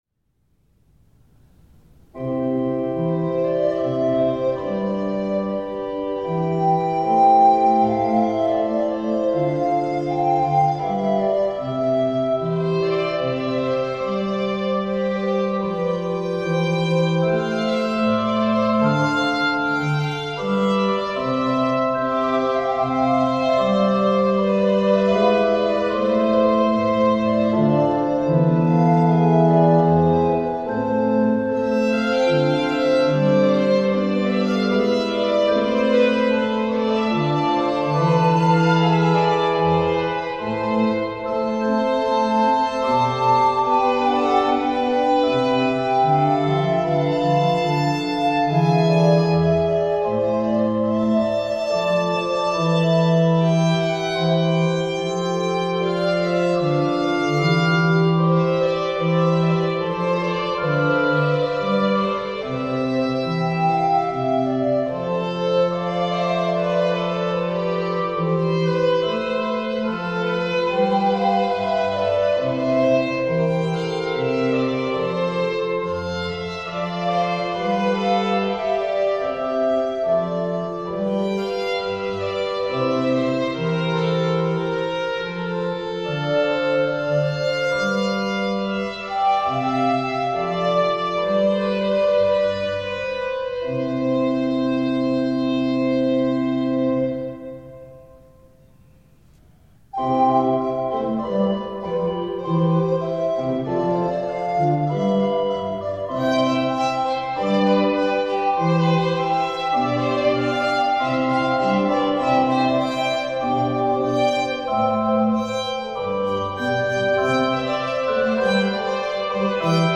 Noch unter normalen Musizierbedingungen wurde die folgende Triosonate für Flöte, Violine und Orgel eingespielt.
Dolce-Allegro-Grave-Vivace
Flöte
Violine
Orgel
(aufgenommen am 20.3.20 in St. Josef)